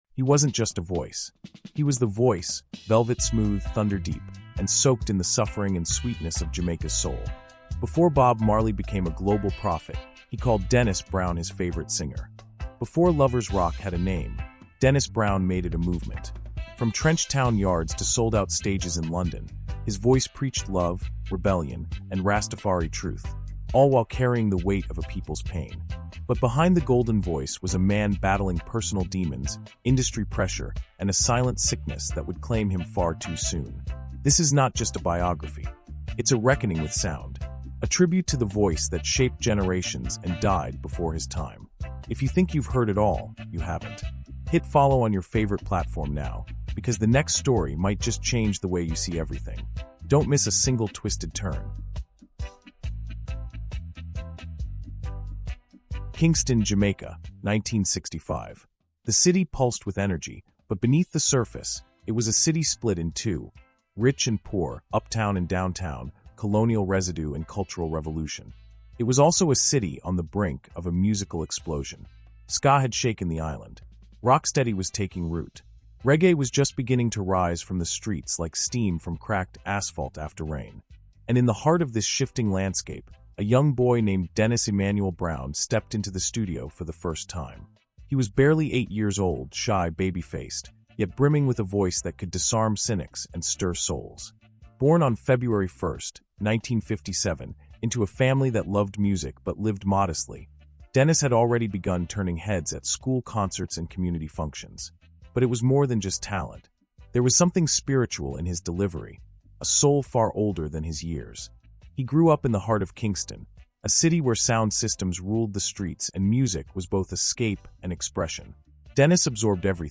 CARIBBEAN HISTORY: Dennis Brown — The Crown Prince Who Sang the People’s Pain is a powerful, emotionally immersive audiobook documentary exploring the life, music, and legacy of reggae icon Dennis Emmanuel Brown. Widely known as Bob Marley’s favorite singer, Dennis Brown rose from the heart of Caribbean history to become a cultural symbol of resilience, black history, and Caribbean music excellence.